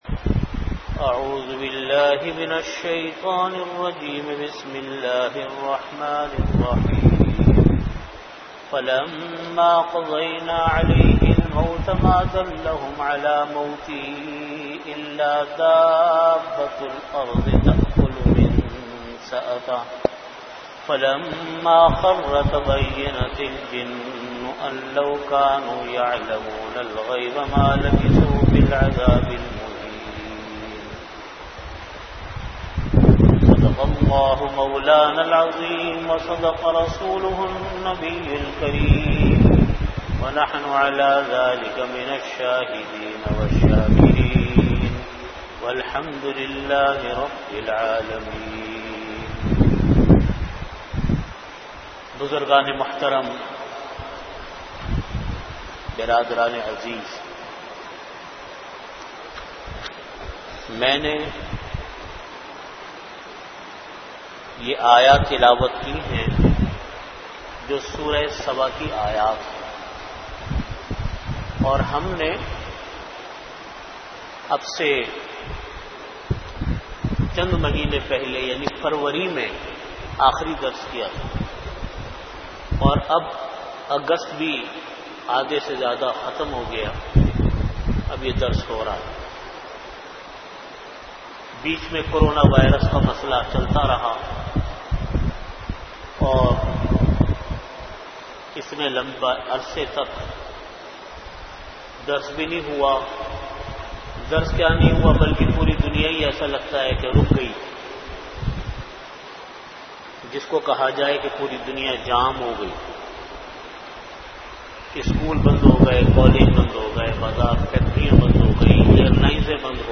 Category: Tafseer
Time: After Asar Prayer Venue: Jamia Masjid Bait-ul-Mukkaram, Karachi